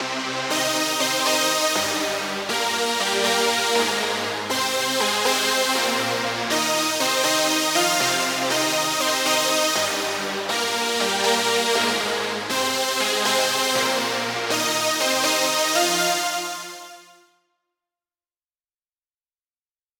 I opted for an instance of Spectrasonics’ Omnisphere and picked a pretty epic synth patch.
The MIDI plays back using the Omnisphere patch.